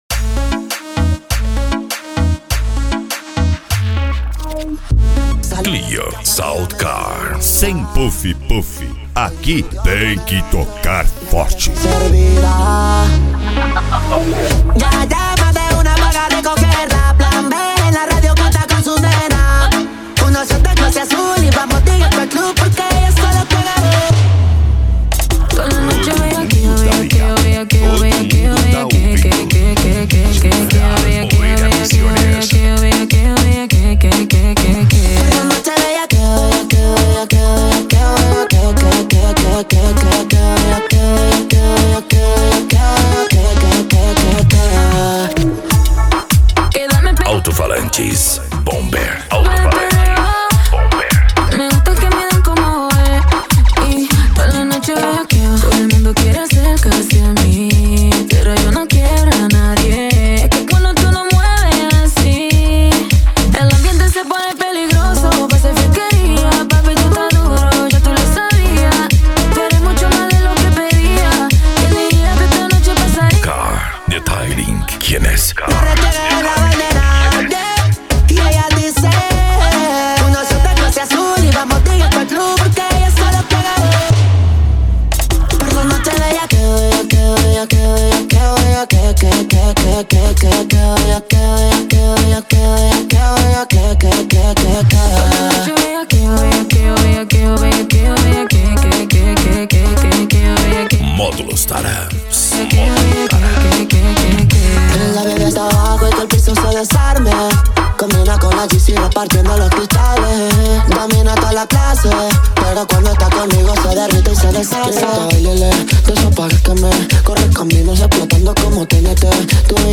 Bass
Eletronica
Modao
Remix